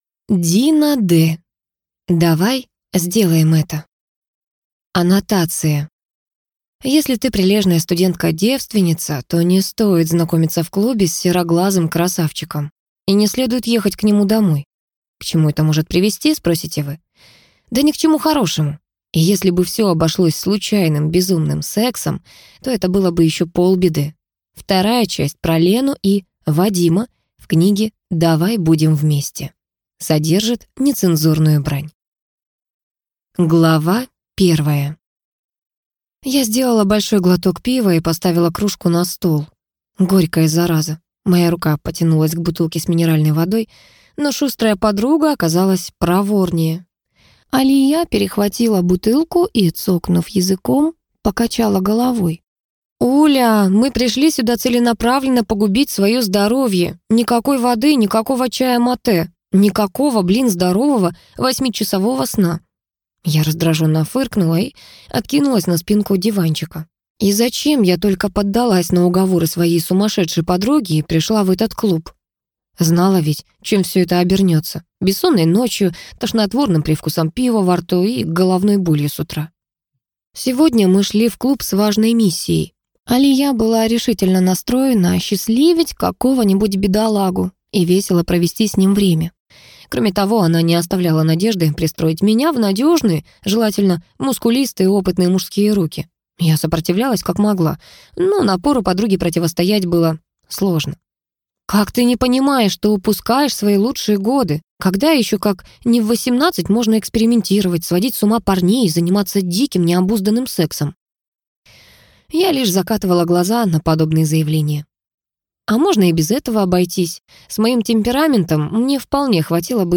Аудиокнига Давай сделаем это | Библиотека аудиокниг